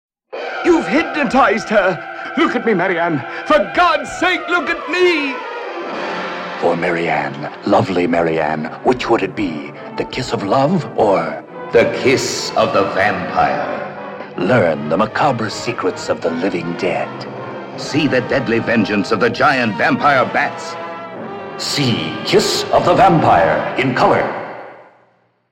Radio Spots
The radio spots presented here are atmospheric, and capture the thrills in store for the theatergoer.